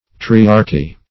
Search Result for " triarchy" : The Collaborative International Dictionary of English v.0.48: Triarchy \Tri"ar*chy\, n.; pl.